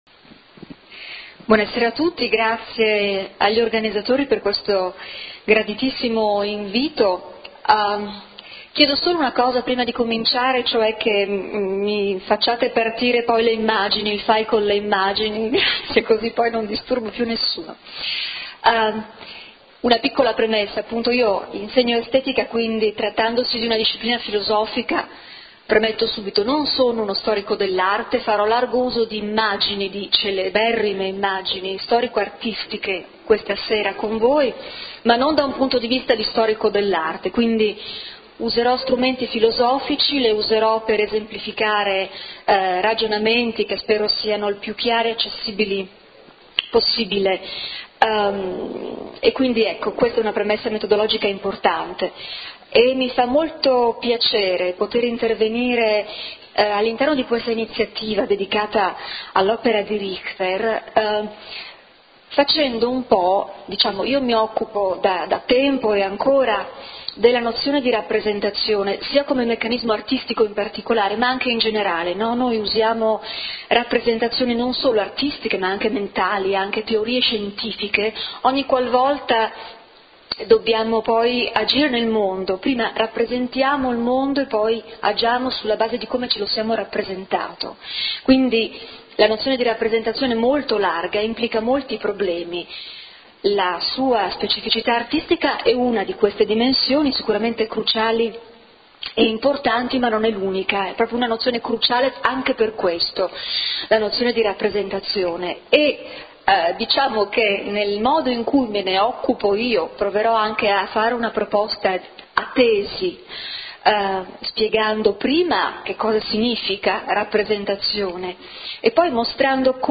LECTURE / Che cosa significa rappresentare